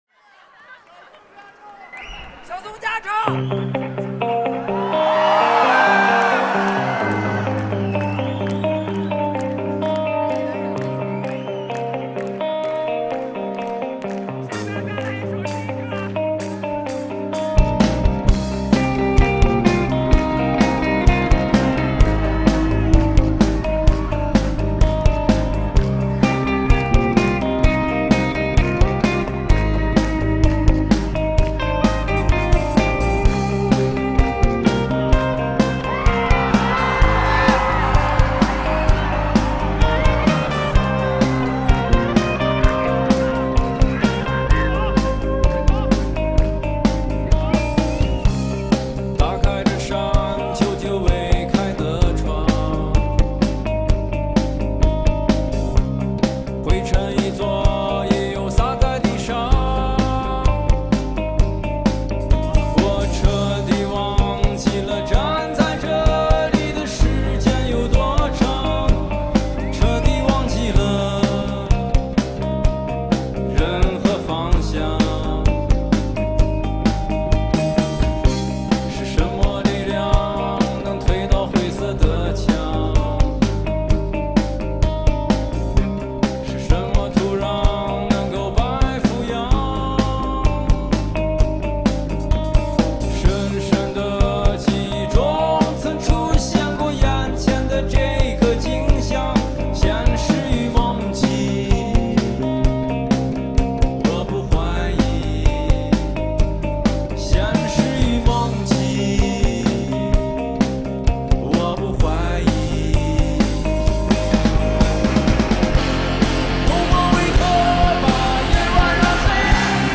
爵士鼓